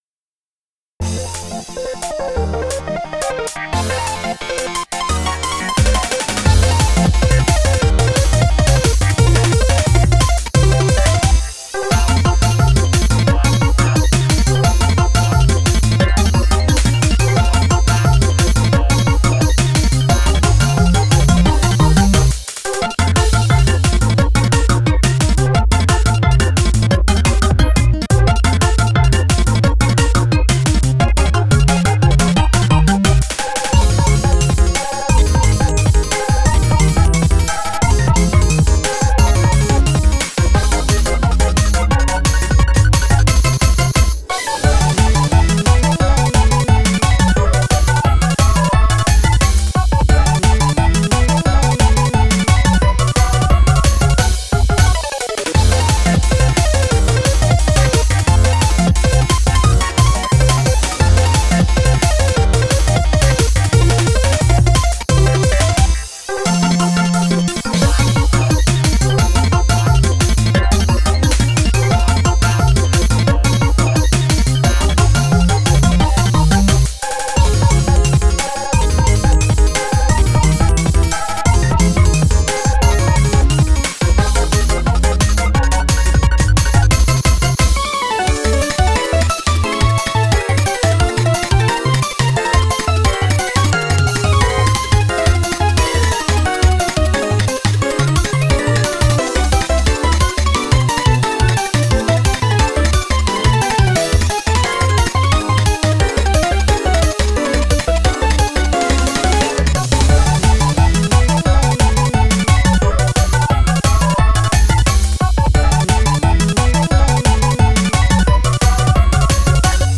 ボーカルなし版